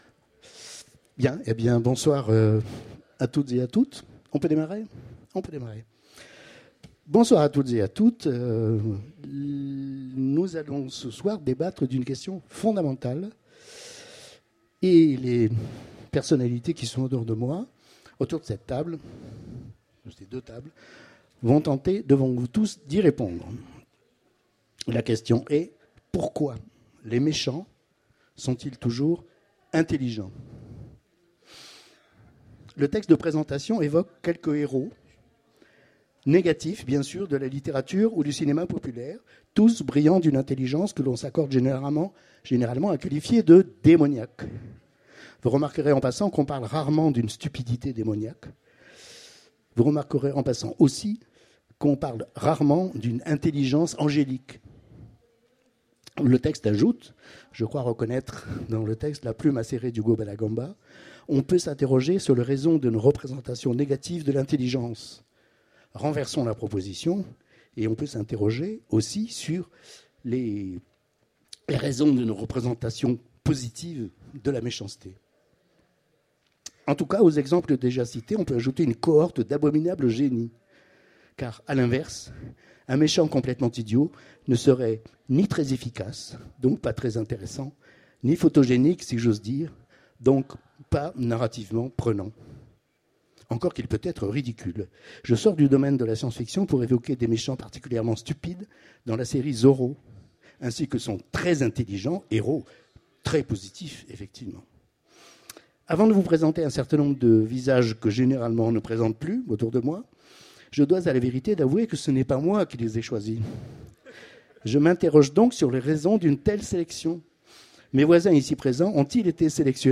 Mots-clés Intelligence Conférence Partager cet article